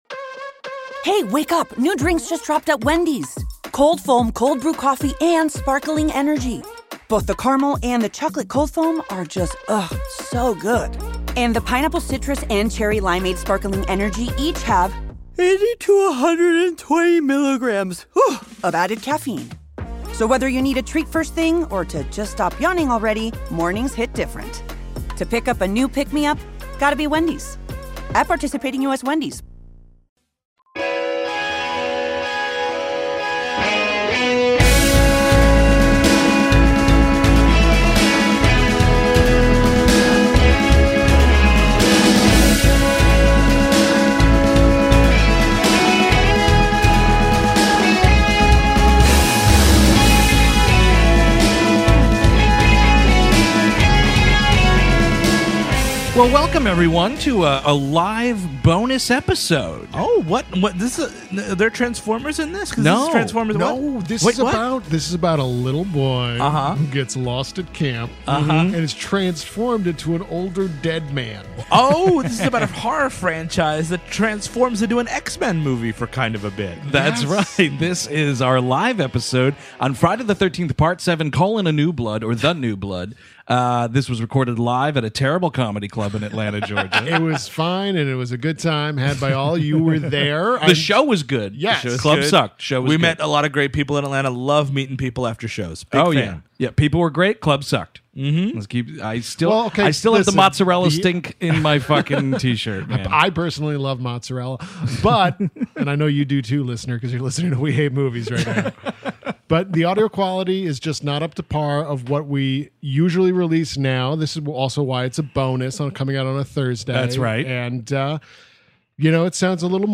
Recorded at the Punchline in April of 2017, listen as the gang braves the waves of enticing mozzarella stick odor to talk about the seventh film in the slasher series! Why does Jason have to look like a lizard person in this?